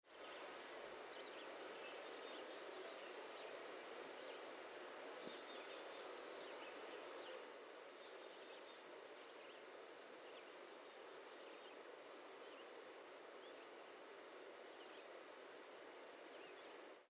Звуки кошачьего пересмешника
Их песни состоят из случайных, но повторяющихся нот, включающих имитации других видов и неожиданные звуки.
Чириканье маленького птенца